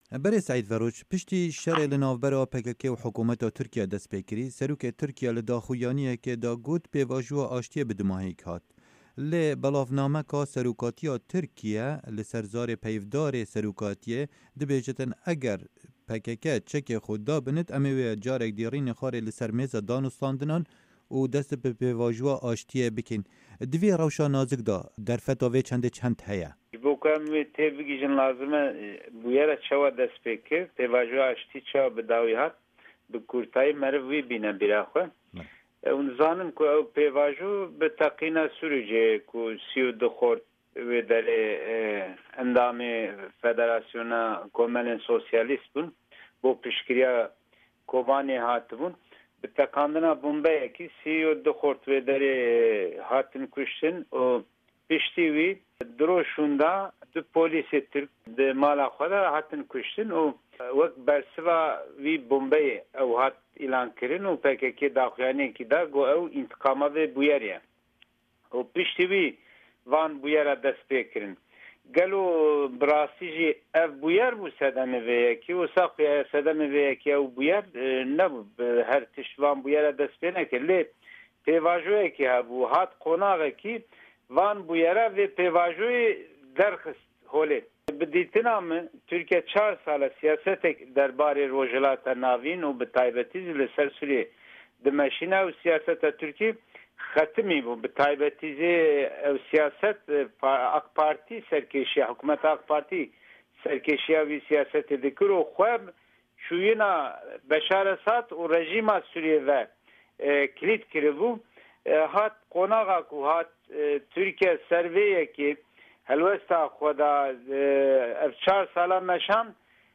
Hevpeyvin